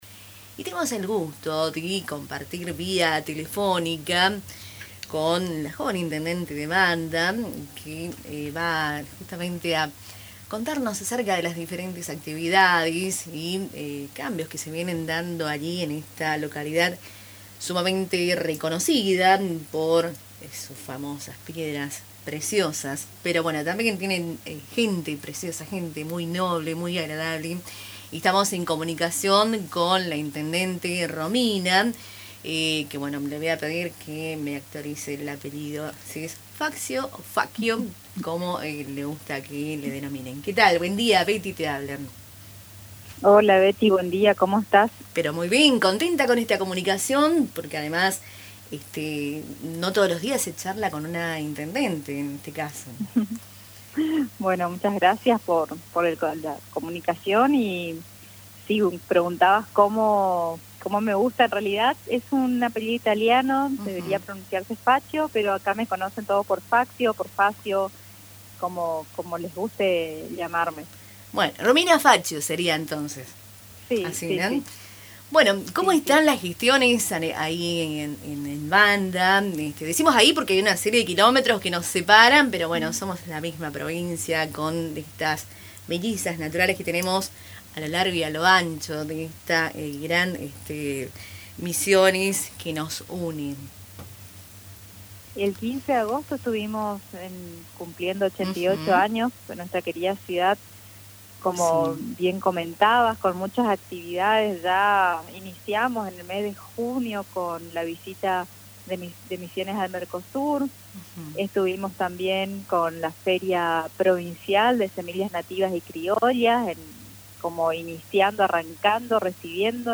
En una charla telefónica con Radio Tupa Mbae, la joven intendente de Wanda, Ingrid Romina Faccio, compartió detalles sobre las diversas actividades y cambios que se están llevando a cabo en esta localidad, conocida por sus famosas piedras preciosas y su gente hospitalaria.